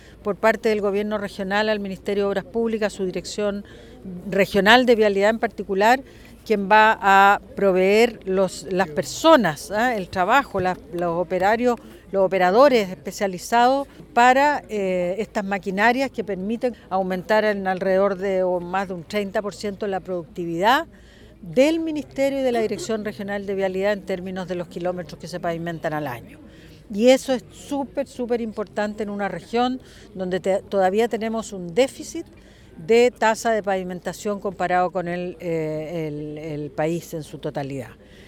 El viernes recién pasado, durante su visita en la Región de Los Lagos, la Ministra de Obras Públicas Jessica López participó en la recepción del pool de maquinarias que el Gobierno Regional adquirió para la Dirección de Vialidad en la Provincia de Osorno y en la firma del Convenio para el plan de recambio de puentes.
El Gobierno Regional de Los Lagos, adquirió tres nuevos pools de maquinarias uno para cada provincia, los que serán utilizados por el personal de la Dirección de Vialidad, ante lo que la Ministra López destacó que permitirá aumentar en más de un 30% los índices de pavimentación en la zona.